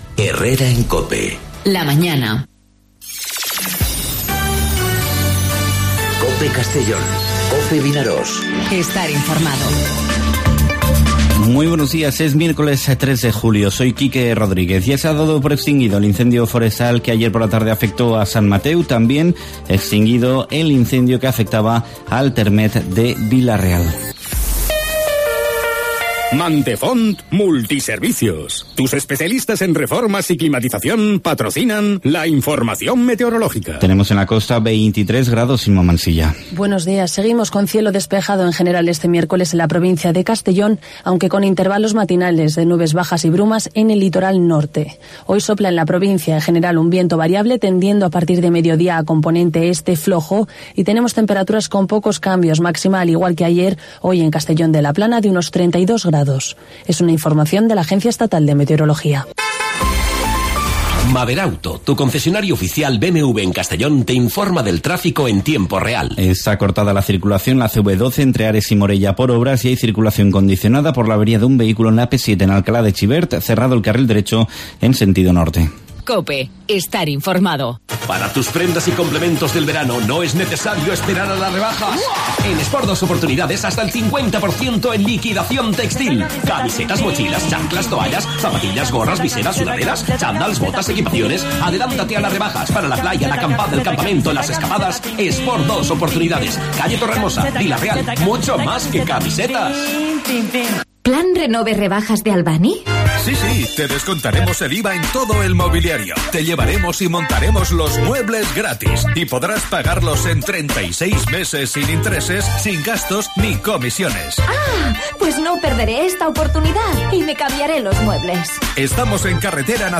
Informativo 'Herrera en COPE' Castellón (03/07/2019)